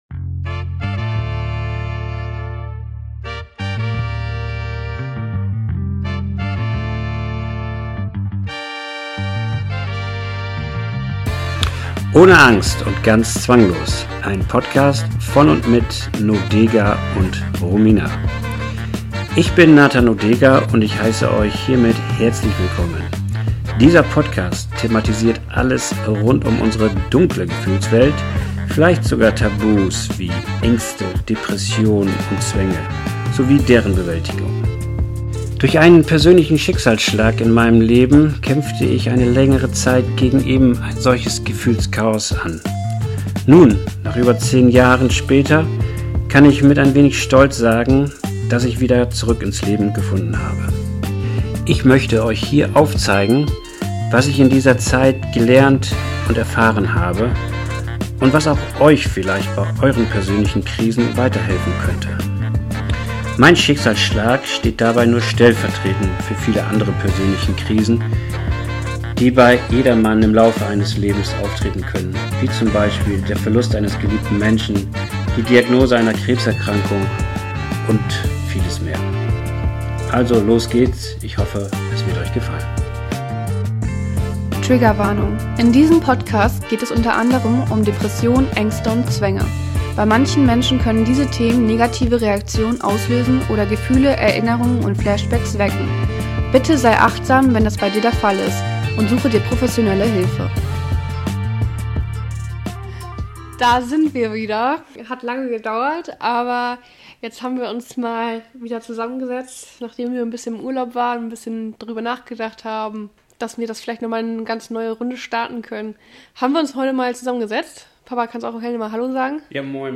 Was passiert, wenn das Leben plötzlich schwer wird und die Lebenslust verschwindet? Persönlich, ehrlich und ohne Schnörkel geben die beiden Einblicke in eigene Erfahrungen und Gedanken.